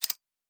pgs/Assets/Audio/Sci-Fi Sounds/MISC/Metal Tools 02.wav
Metal Tools 02.wav